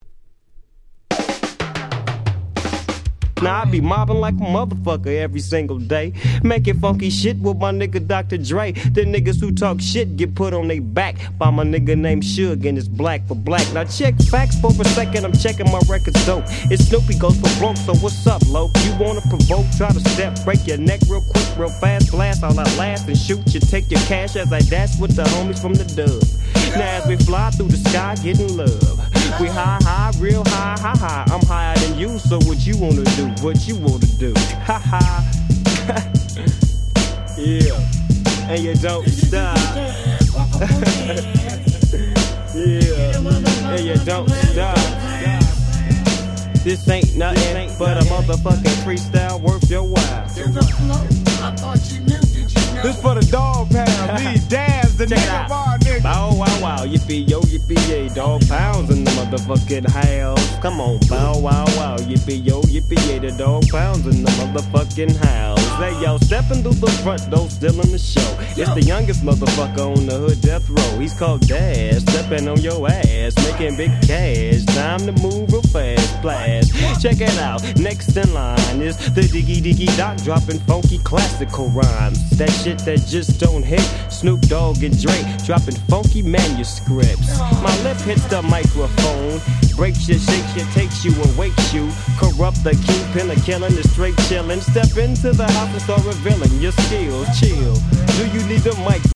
90's West Coast Hip Hop Super Classics !!
G-Rap Gangsta Rap